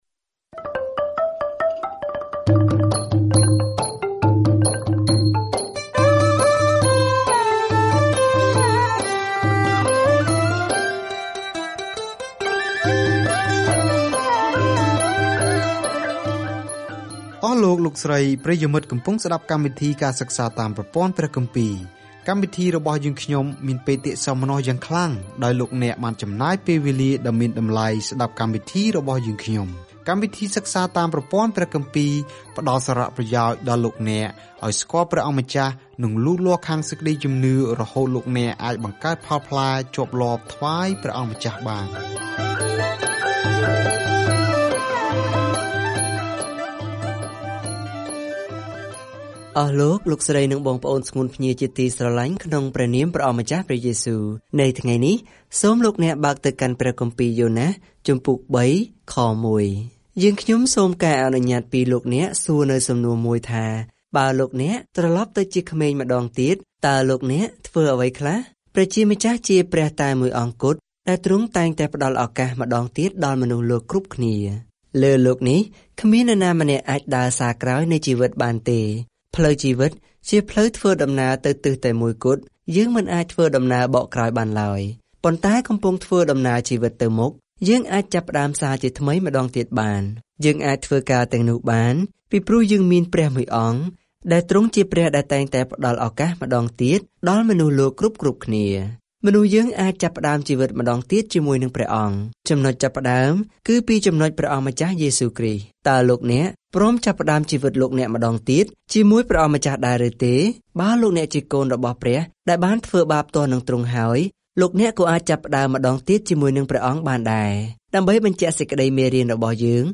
យ៉ូណាសបានផ្សព្វផ្សាយទៅកាន់ខ្មាំងសត្រូវរបស់គាត់ ប៉ុន្តែបន្ទាប់មកបានខឹងនឹងព្រះដែលបានជួយសង្គ្រោះពួកគេ។ ការធ្វើដំណើរជារៀងរាល់ថ្ងៃតាមរយៈយ៉ូណាស ពេលអ្នកស្តាប់ការសិក្សាជាសំឡេង ហើយអានខគម្ពីរដែលបានជ្រើសរើសពីព្រះបន្ទូលរបស់ព្រះ។